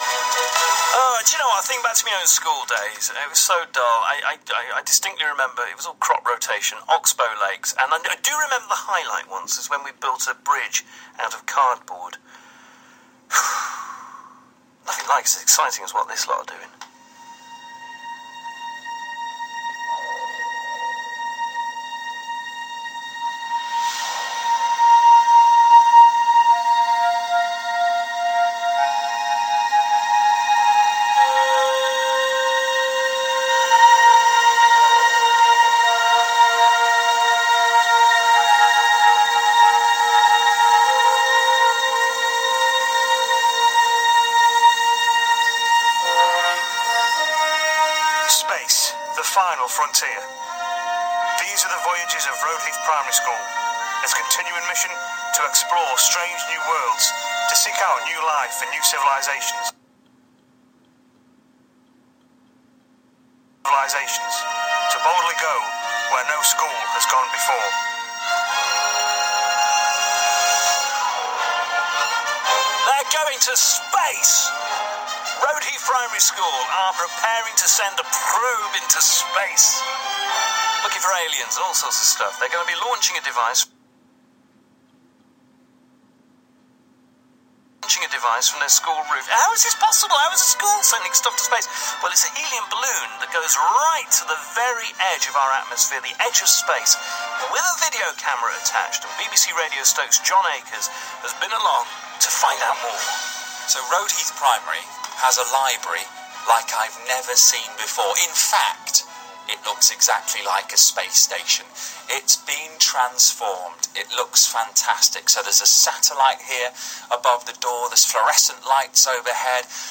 balloon-launch-on-bbc-stoke-radio.mp3